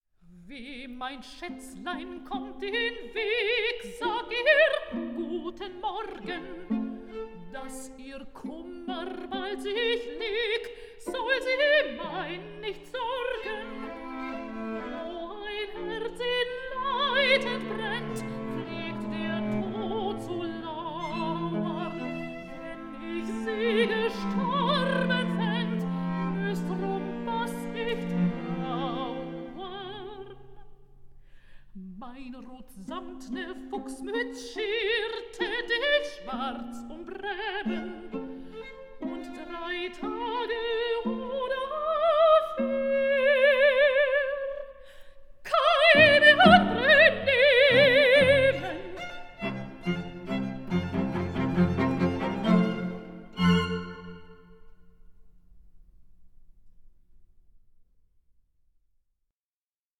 Songs for voice and orchestra
soprano